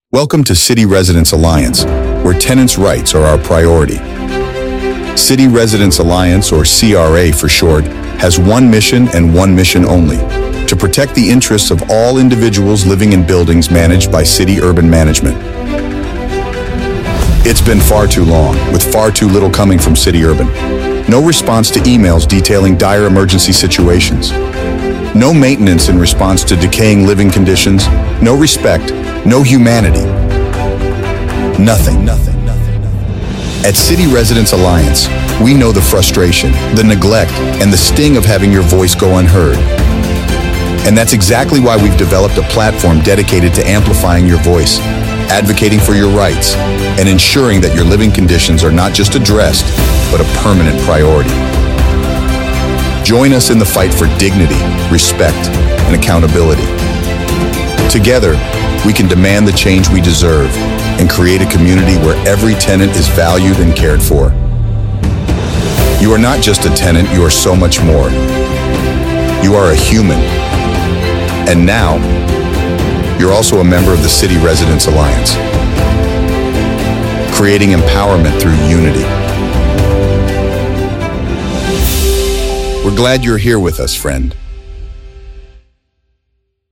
Listen as tenants share their experiences living in the New York City buildings owned and managed by the notorious slumlord.
Trailer: